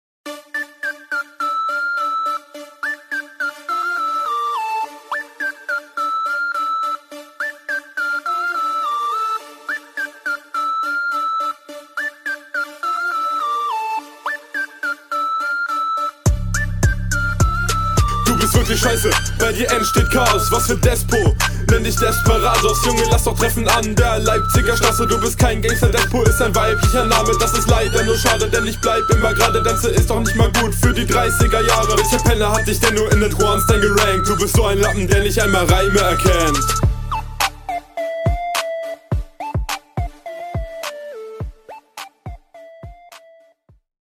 Sehr seltsamer beat :D Mische technisch könnten die Doubles etwas sauberer gesetzt werden.. bzw deine …
beat hat n guten drive wie ein usher beat :-D einstieg kommt gut. flowt stabil …